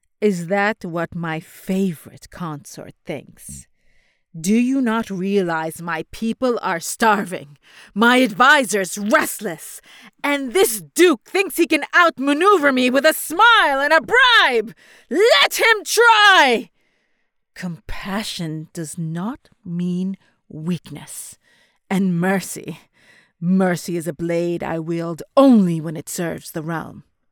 Character Samples
Queen.mp3